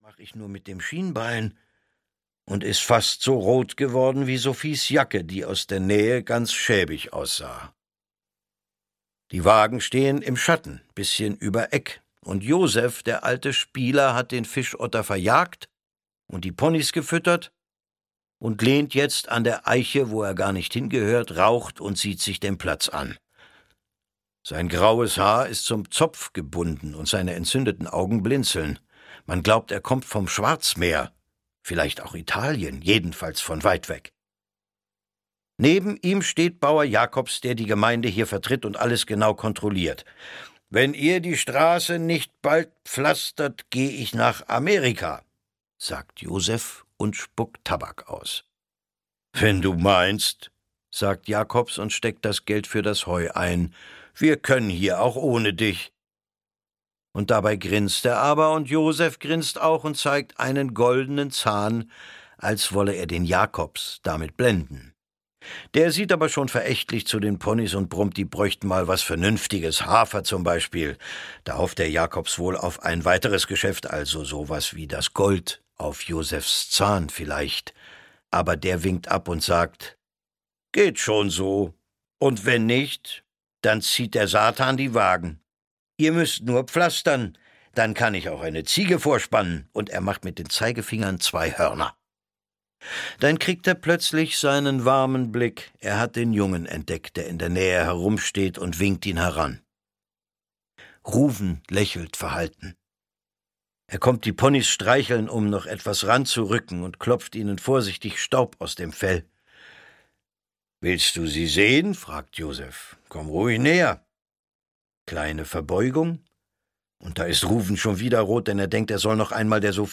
Das letzte Land - Svenja Leiber - Hörbuch